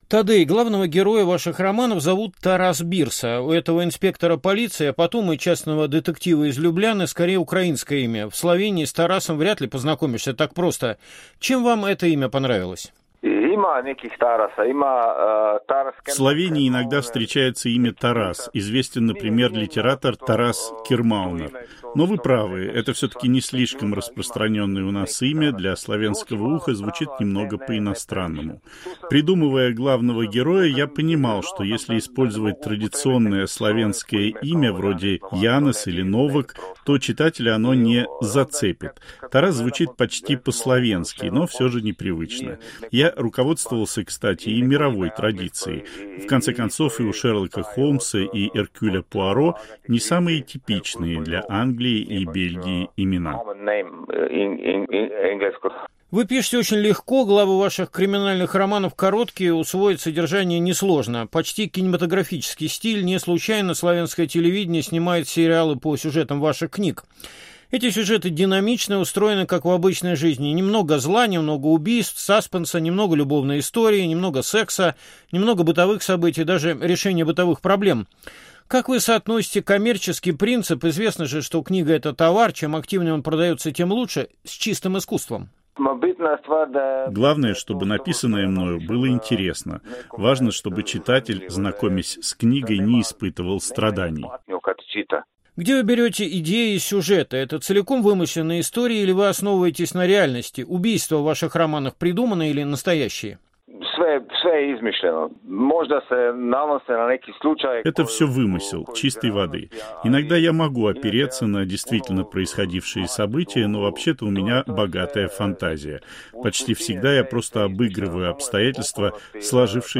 Кинокритик Антон Долин в интервью автору и ведущему программы "Грани времени" Мумину Шакирову – о Путине как о главном кинозлодее XXI века в Европе, певце Shaman, сериале "Слово пацана", дружбе с режиссером Константином Богомоловым и о Тейлор Свифт как феномене американской...